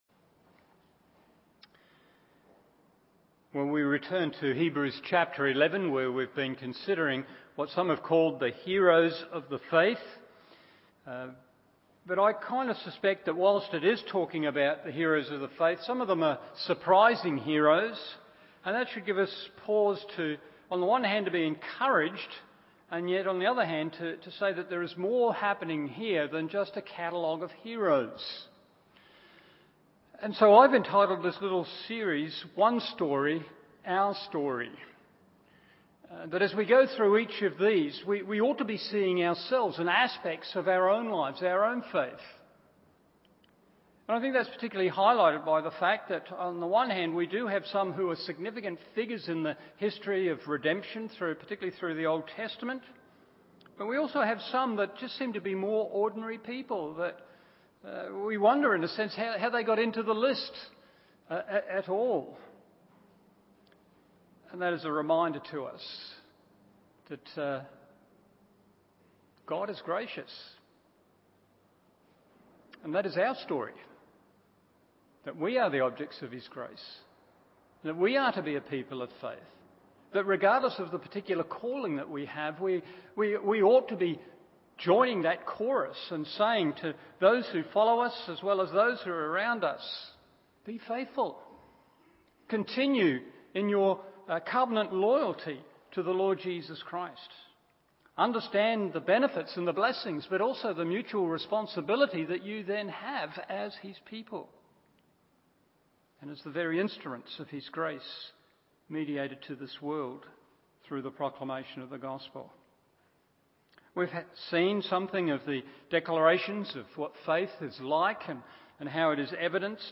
Evening Service Hebrews 11:11-12 1. A Promise Apparently Impossible 2. A Promise Assured and Believed 3. A Promise Accomplished and Celebrated…